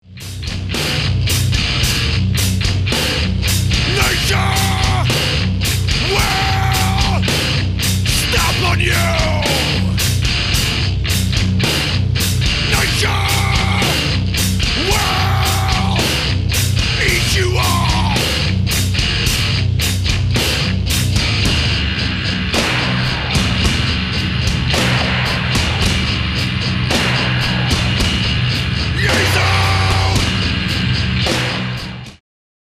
industrial metal